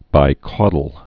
(bī-kôdl)